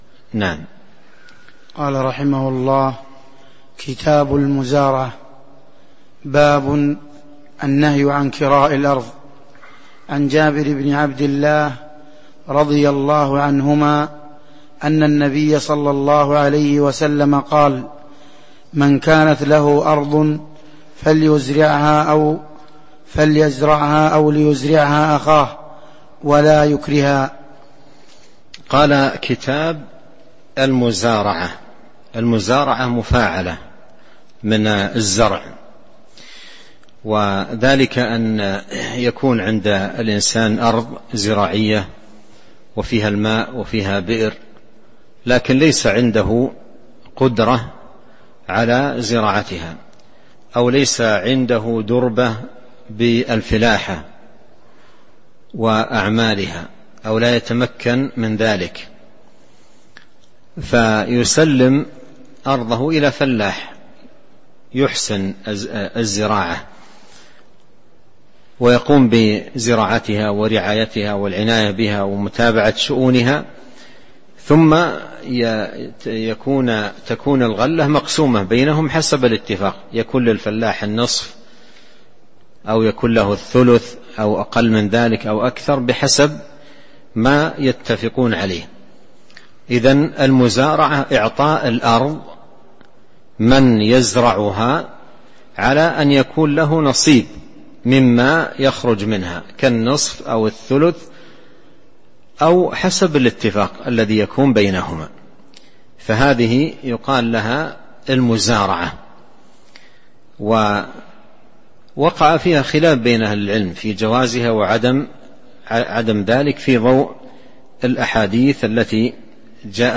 تاريخ النشر ١٣ صفر ١٤٤٣ هـ المكان: المسجد النبوي الشيخ: فضيلة الشيخ عبد الرزاق بن عبد المحسن البدر فضيلة الشيخ عبد الرزاق بن عبد المحسن البدر باب النهي عن كراء الأرض (01)